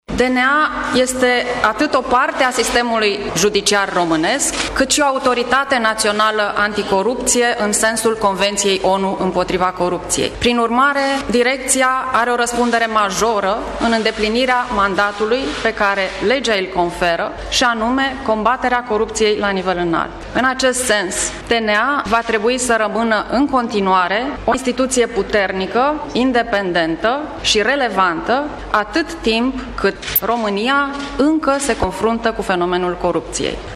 Într-o scurtă declaraţie, Anca Jurma a spus că se va strădui să aibă un mandat echilibrat, în care DNA să continue activitatea pe care a început-o în urmă cu 15 ani: